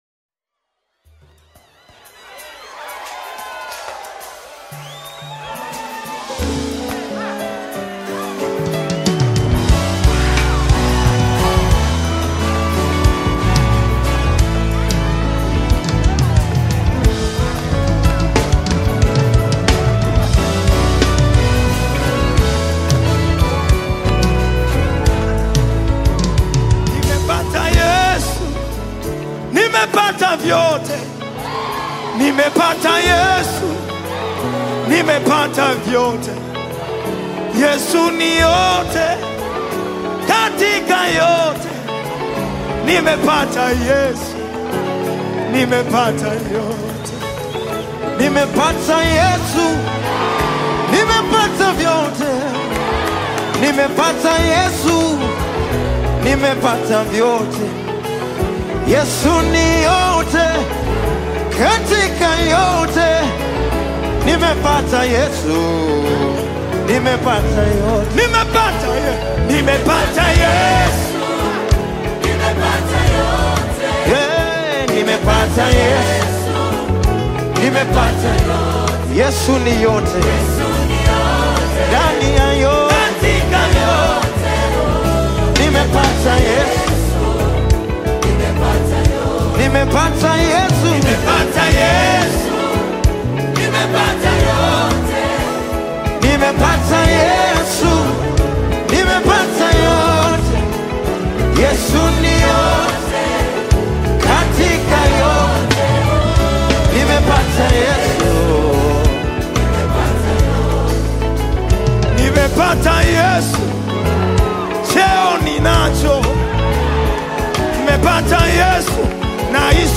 Nyimbo za Dini Worship music
Worship Gospel music
Gospel song